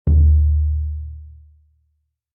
Surdo-1.mp3